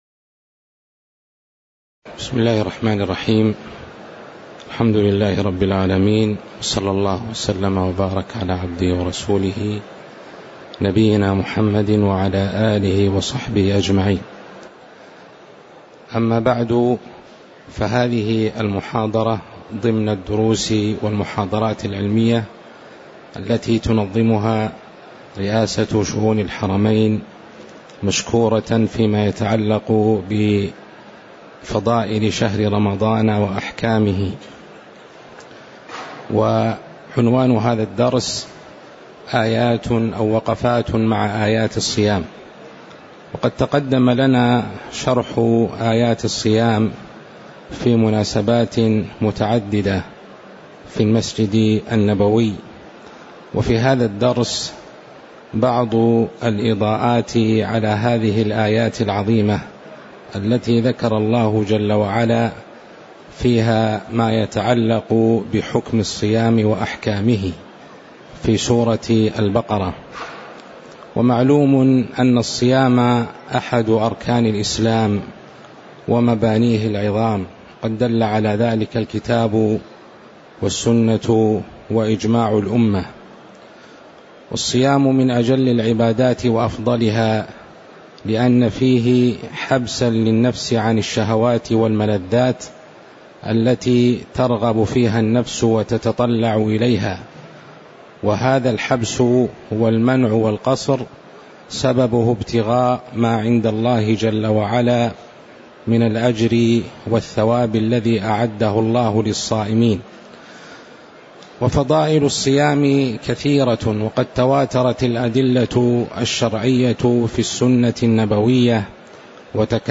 تاريخ النشر ١٢ رمضان ١٤٤٠ هـ المكان: المسجد النبوي الشيخ